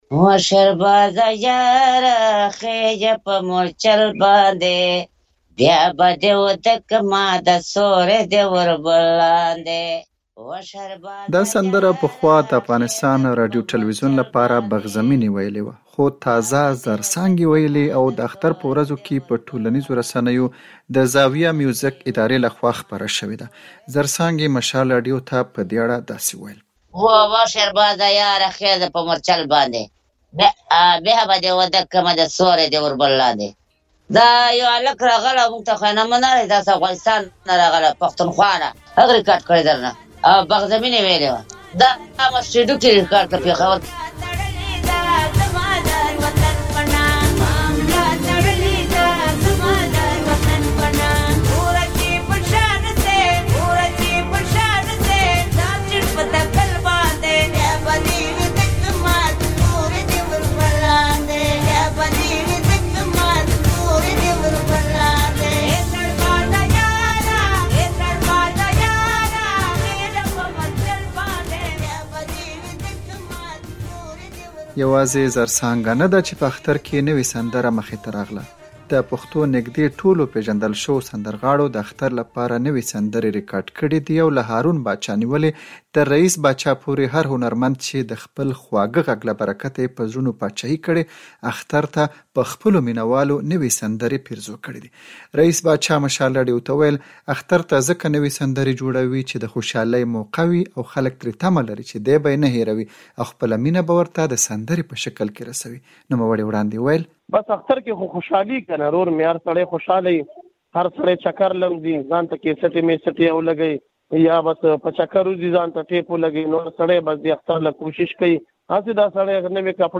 راپور دلته واورئ